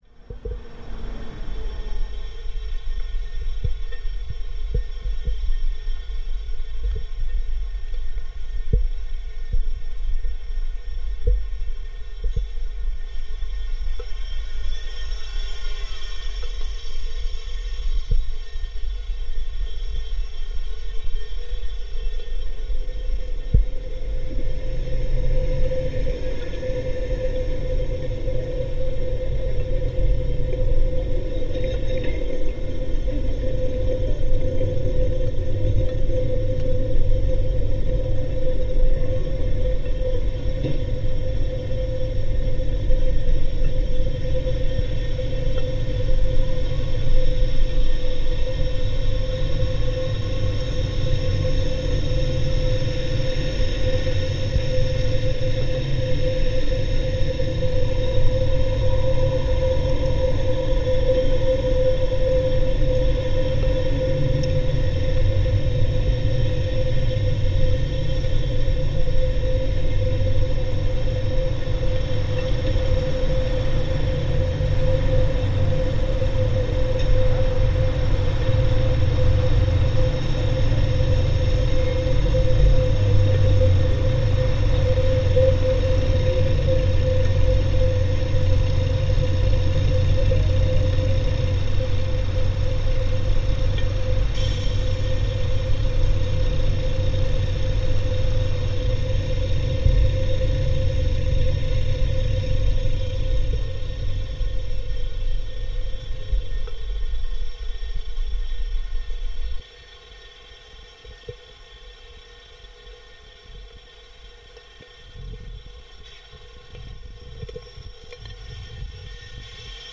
remixes the sounds of the square, filtered ‘on site’ with two different sized piezo transducers, and electromagnetic recordings of bus stop electricity for the LED signs, as well as aspects of the first recording. No software effects used in this recording.